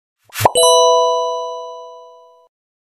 Notif Pesan Simple Elegan viral TikTok
Suara notifikasi simpel, elegan, dan kekinian ini lagi viral banget di TikTok. Cocok buat kamu yang suka notifikasi yang nggak berisik tapi tetap stylish.
nada-notifikasi-pesan-simple-elegan.mp3